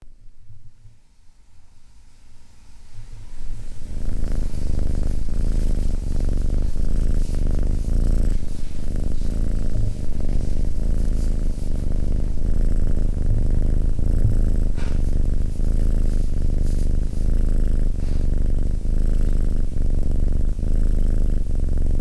Звуки мурчания кошек
Долгое успокаивающее мурлыканье кошки